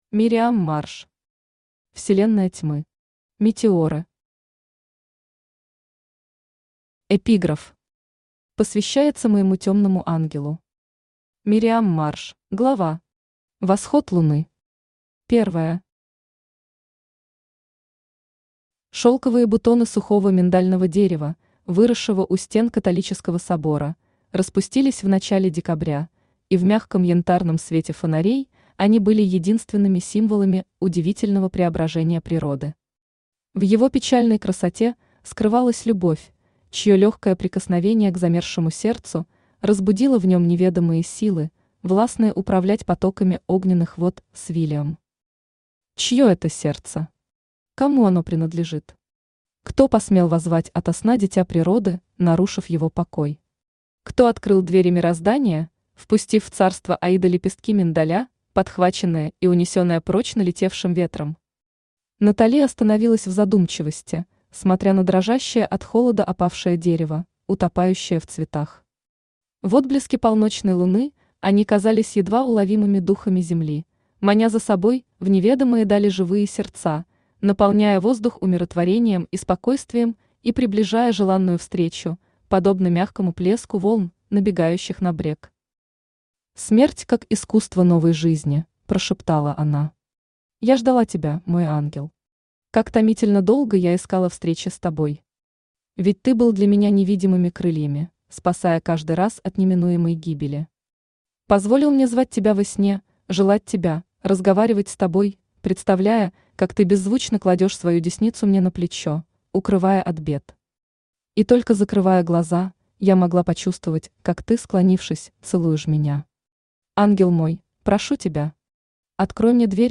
Aудиокнига Вселенная Тьмы. Метеоры Автор Мириам Марш Читает аудиокнигу Авточтец ЛитРес.